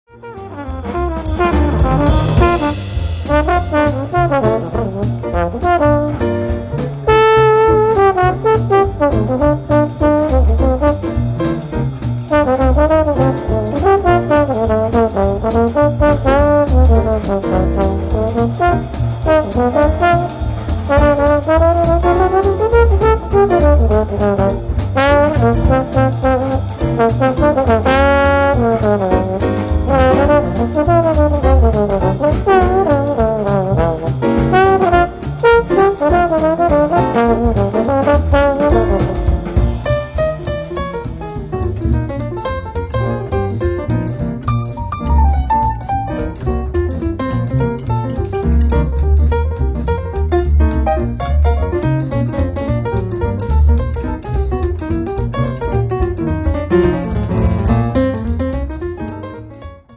tenor sax & clarinet